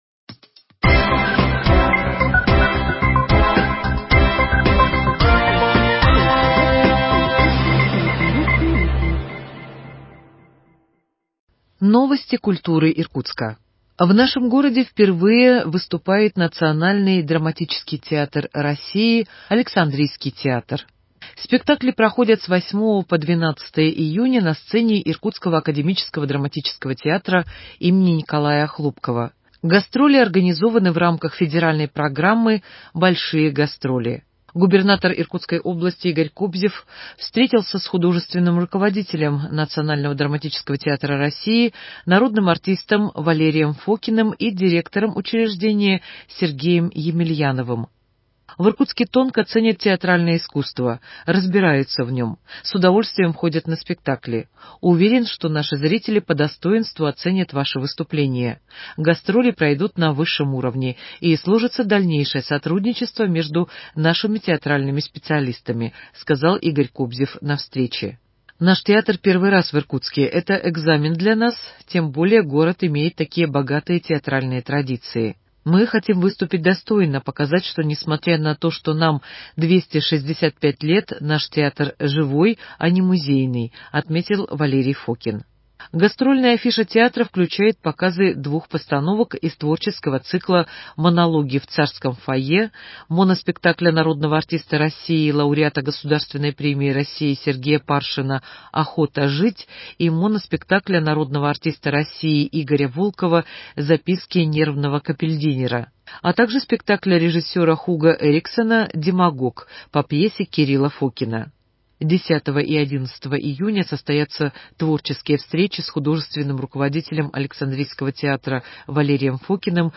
В Иркутске впервые выступит Национальный драматический театр России (Александринский театр). Предлагаем вниманию слушателей репортаж с пресс-конференции театра.